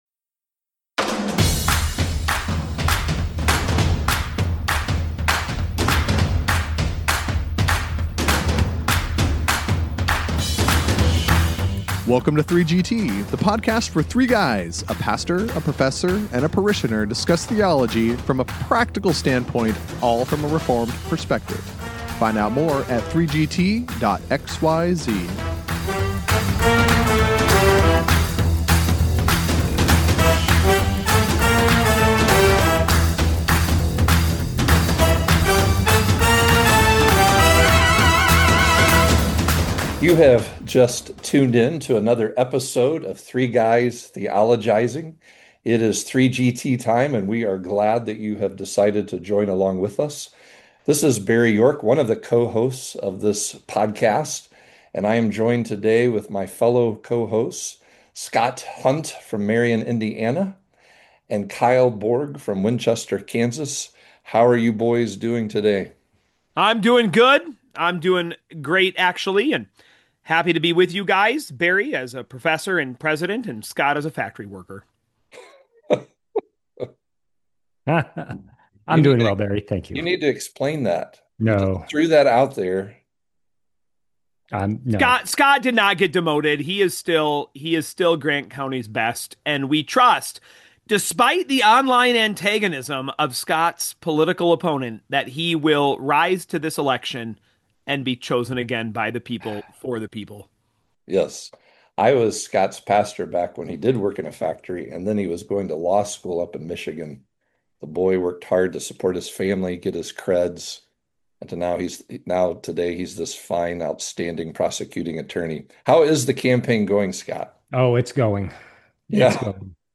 The three guys are back this week with an interesting and edifying conversation in the arena of homiletics. Specifically, the gents share their thoughts on the expository, or expositional, method of preaching God’s Word to his people.